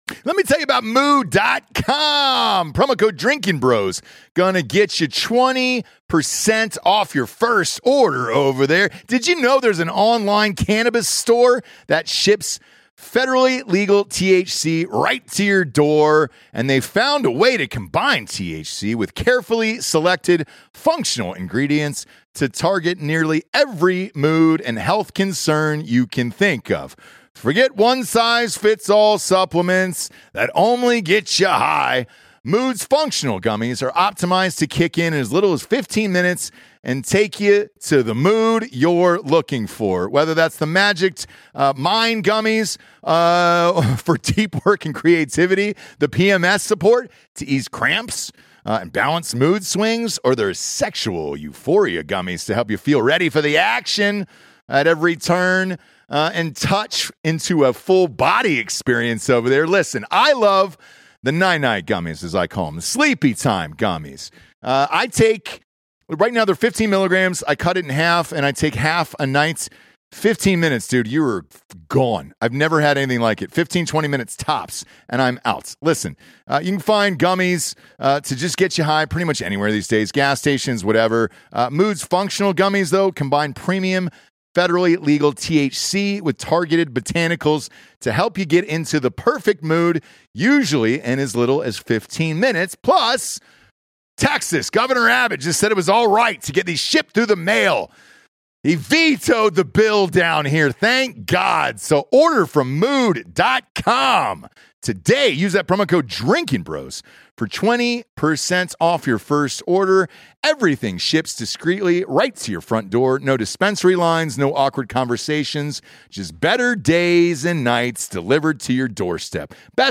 Episode 808 - Special Guest Former White House Press Secretary Dana Perino